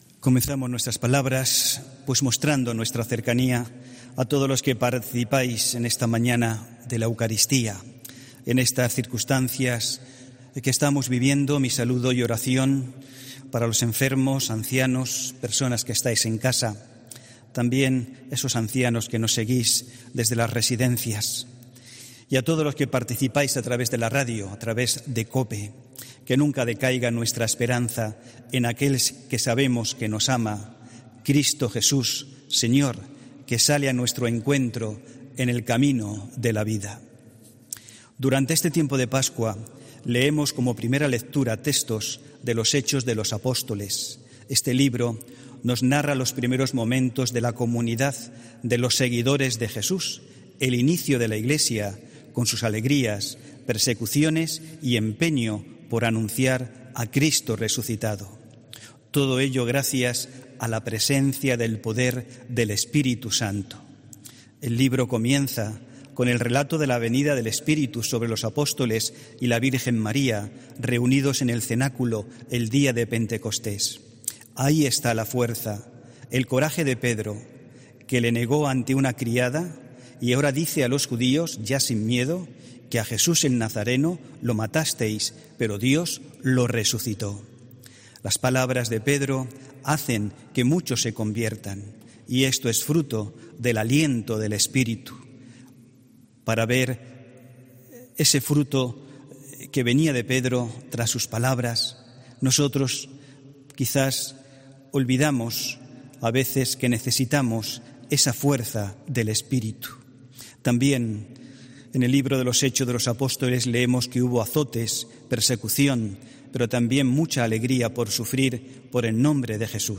HOMILÍA 26 ABRIL 2020